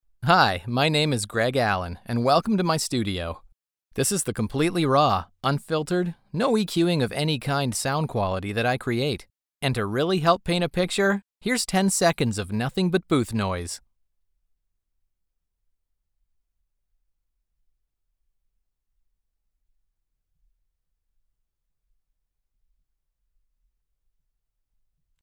Male
Bright, Confident, Corporate, Friendly, Natural, Warm, Approachable, Assured, Character, Cheeky, Conversational, Cool, Energetic, Engaging, Funny, Reassuring, Sarcastic, Soft, Upbeat, Versatile, Wacky, Witty, Young
Canadian English (native) American English
commercial.mp3
Microphone: Rode NT1-A
Audio equipment: Rode NT1-A mic, Focusrite Scarlett 2i2 preamp, Soundproof, Rockwool acoustic panel treated recording space